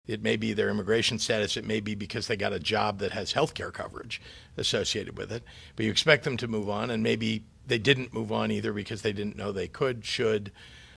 Pushing for universal health care, Governor J.B. Pritzker said the audit didn’t tell the whole story.